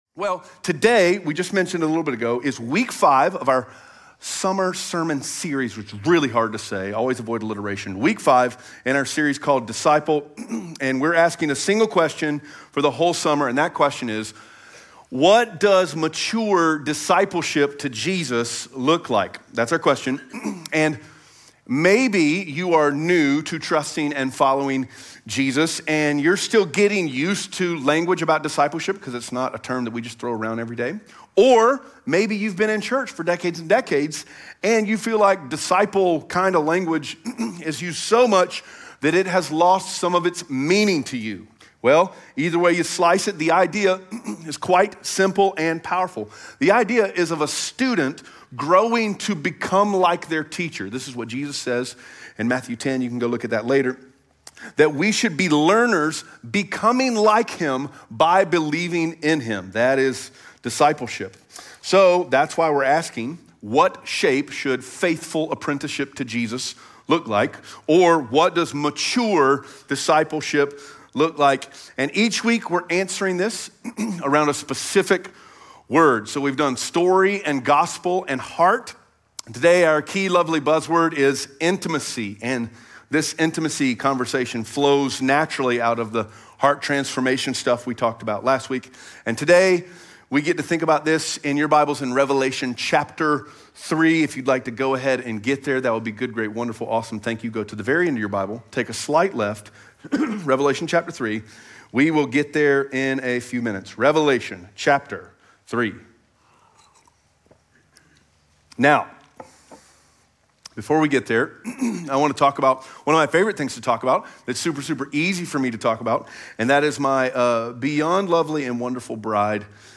Revelation 3:14-22 Audio Sermon Notes (PDF) Ask a Question SERIES SUMMARY At Fellowship Greenville, we desire to reintroduce people to Jesus and the life that He offers.